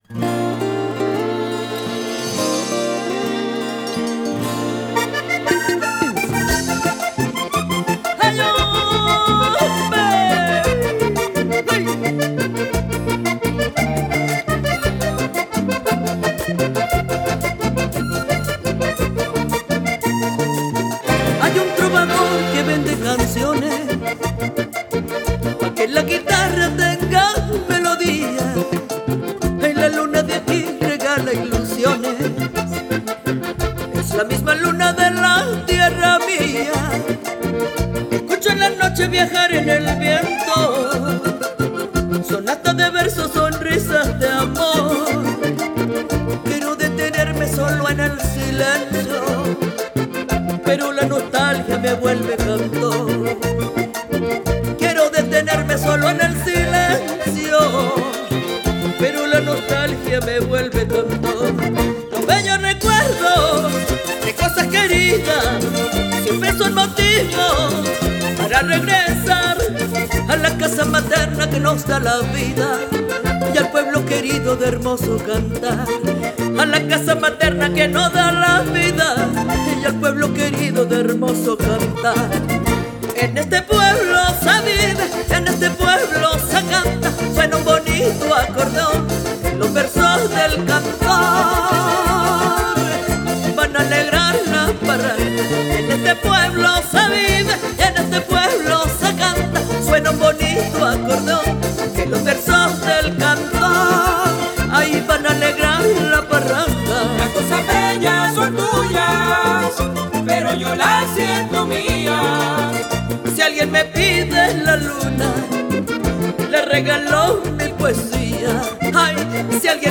(MERENGUE VALLENATO)
Acordeón